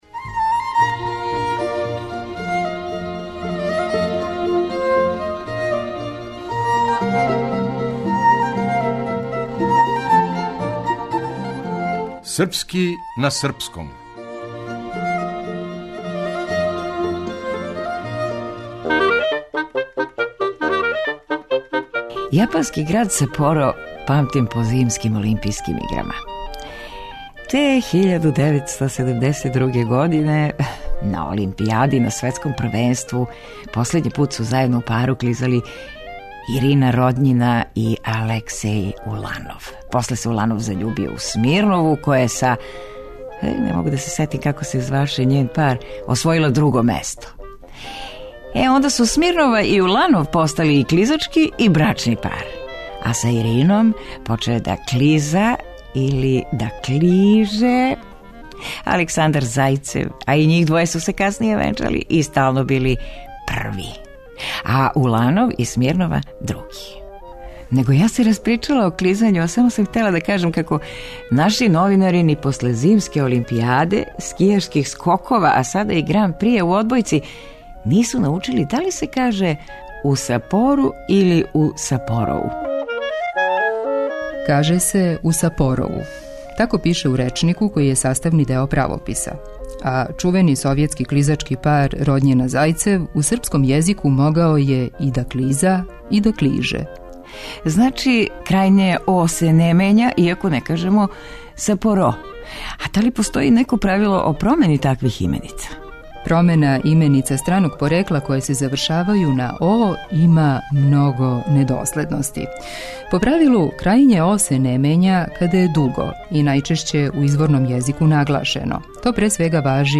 Драмски уметник: